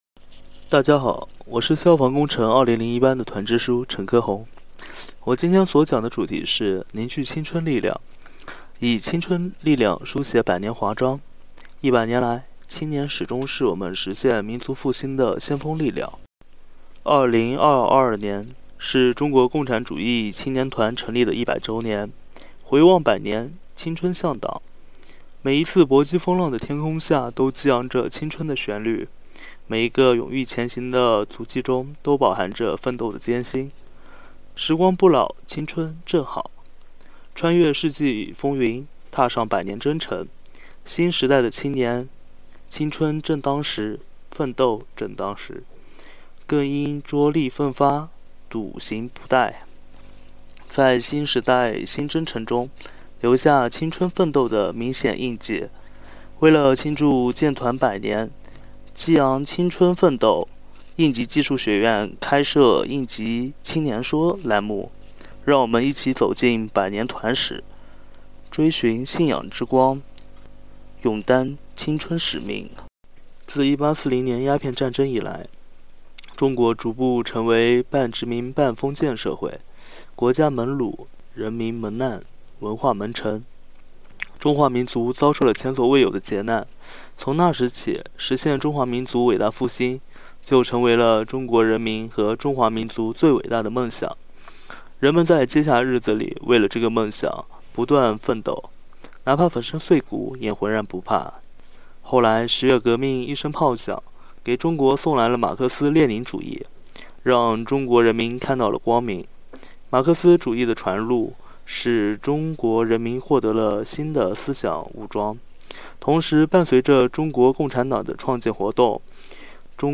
应急青年说：团史宣讲第一期